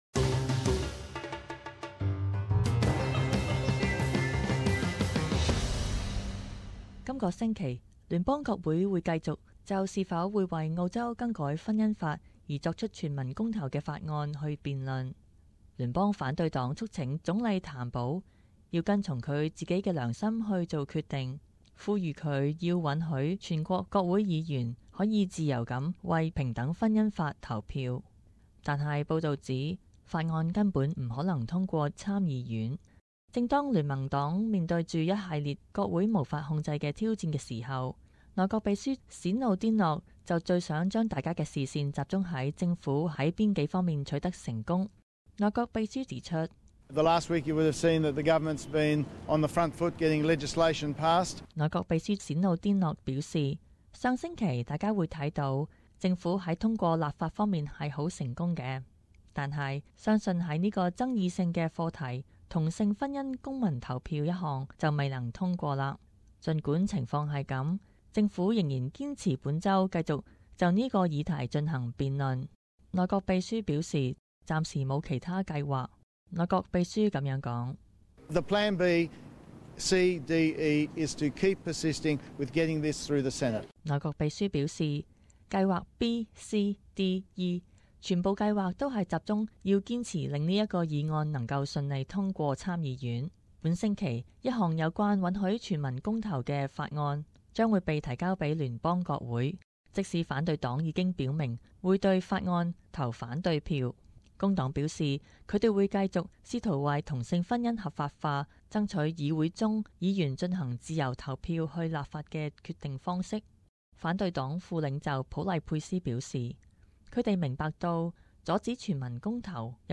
【时事报导】 同性婚姻全民公投法案